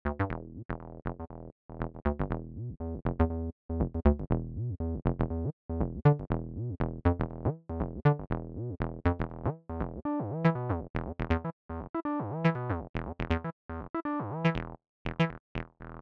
9-2_Chain_Patterns_TB-303_Example.mp3